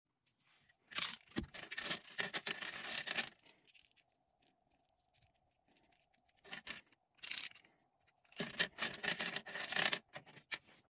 Quaaaaaaack!! 🦆🦆🦆🦆 Sound Effects Free Download